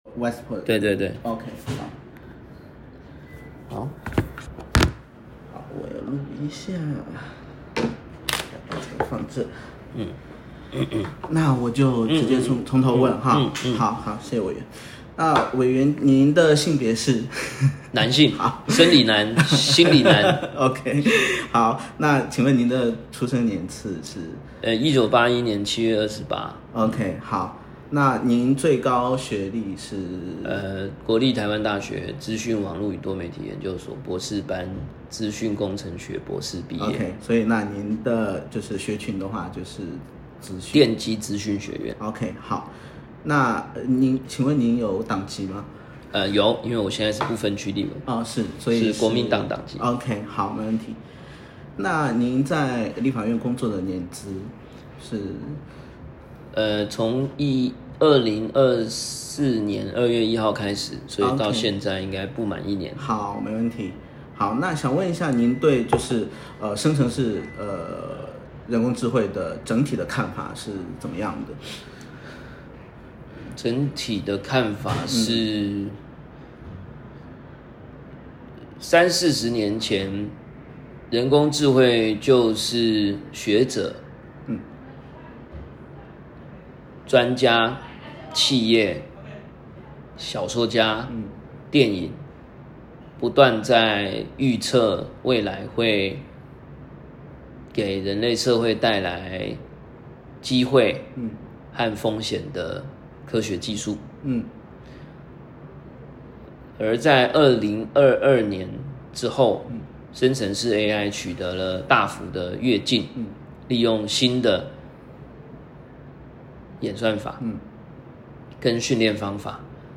「生成式 AI」議題訪談